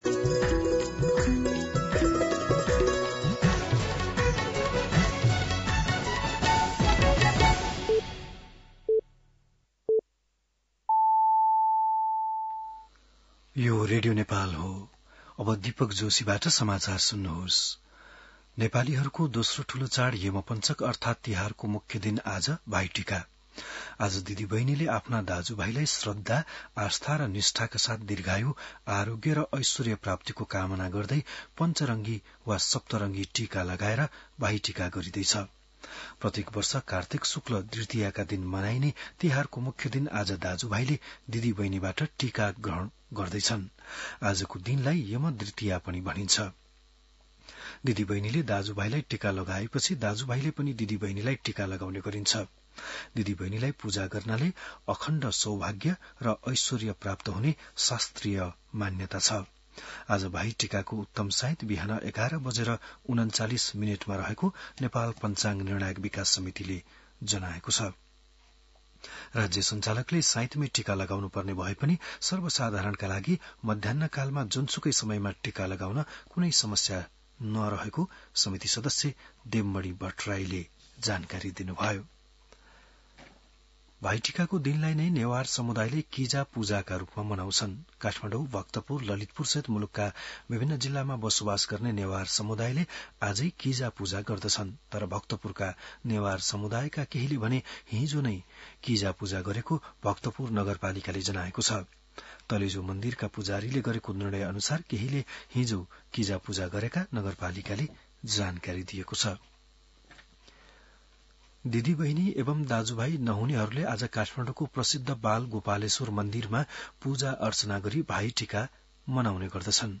बिहान ११ बजेको नेपाली समाचार : १८ पुष , २०२६
11-am-nepali-news.mp3